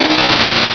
Cri d'Armulys dans Pokémon Rubis et Saphir.